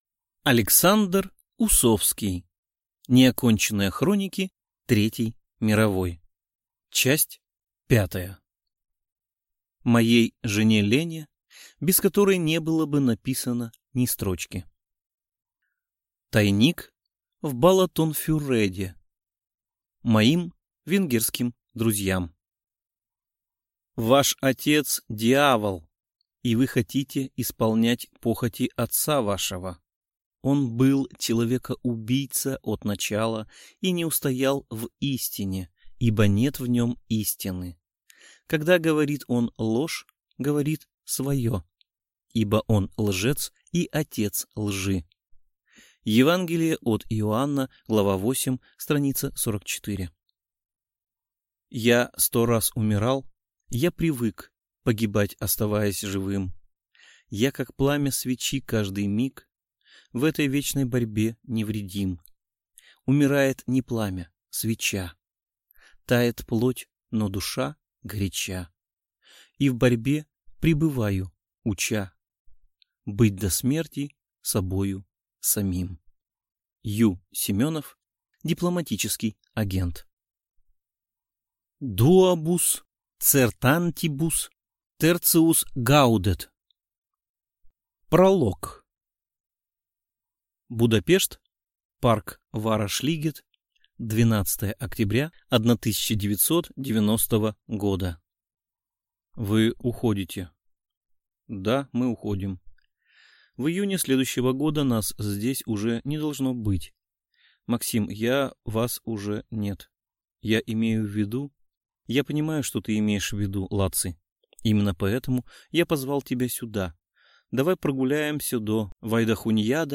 Аудиокнига Тайник в Балатонфюреде | Библиотека аудиокниг